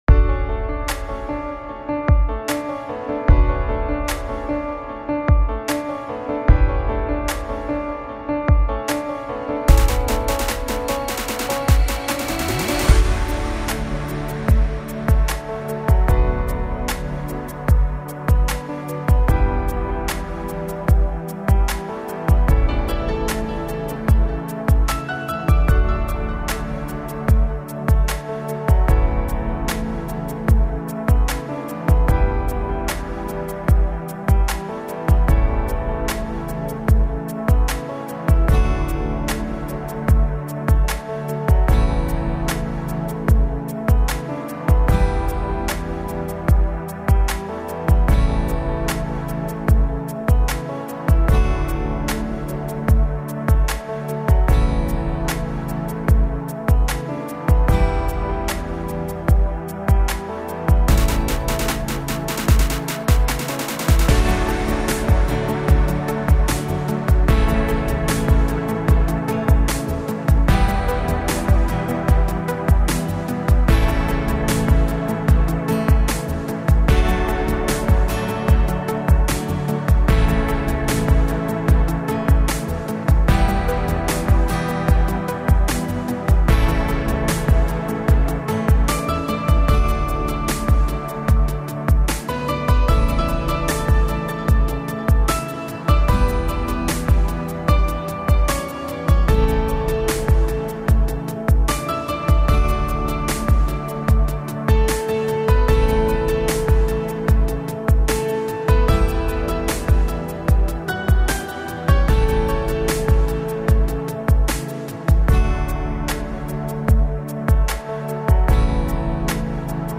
دانلود بیت ارندبی
موزیک بی کلام